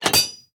smithing_table2.ogg